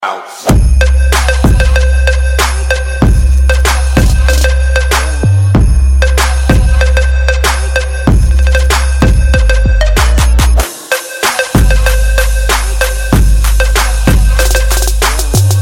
Trap ringtone music Error!